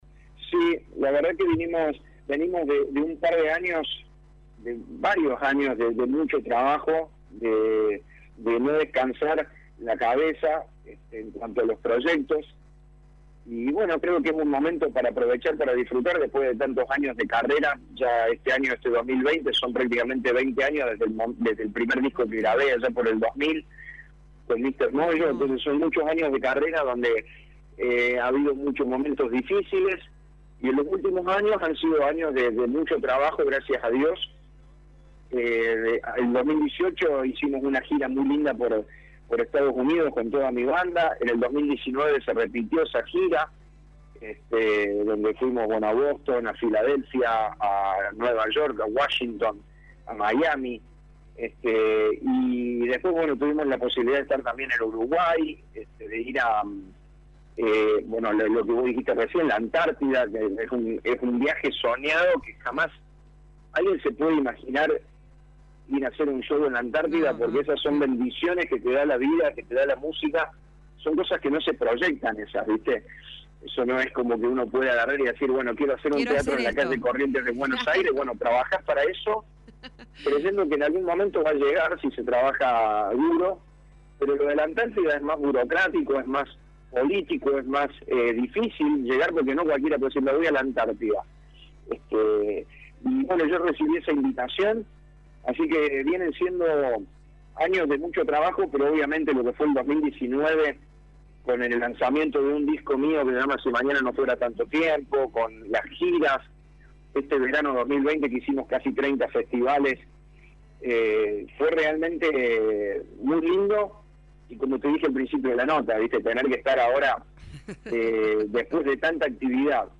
lo reflejó en la entrevista con Radio Show.